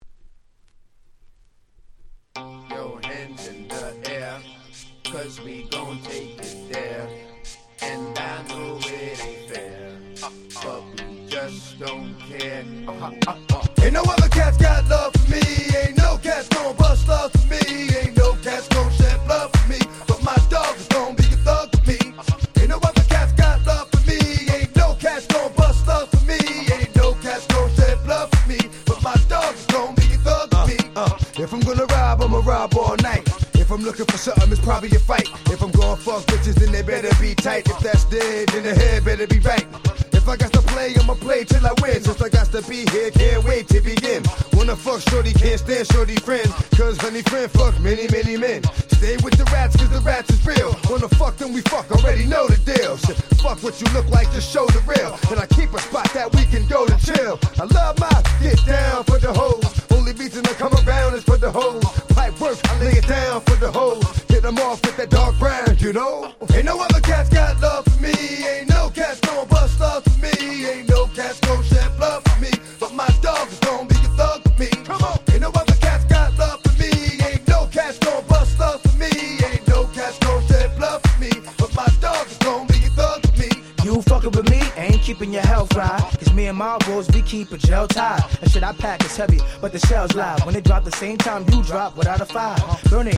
【Media】Vinyl 12'' Single
※一部試聴ファイルは別の盤から録音してございます。